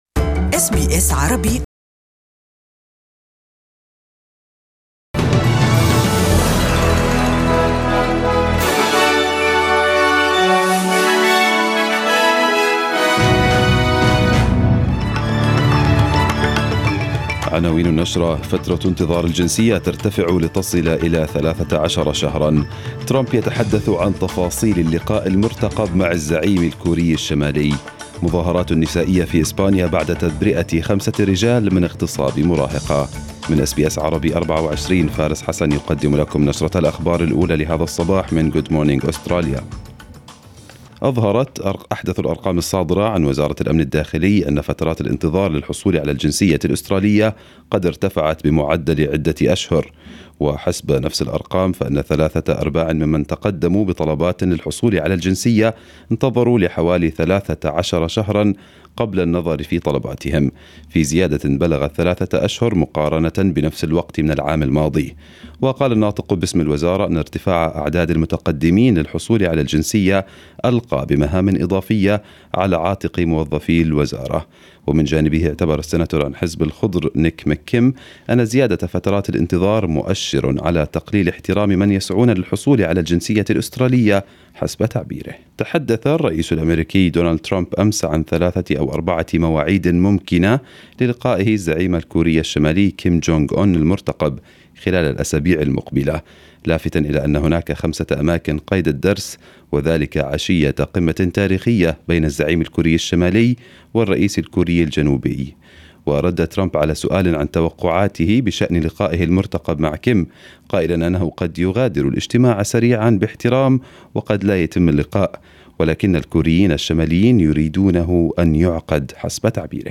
Arabic News Bulletin 27/04/2018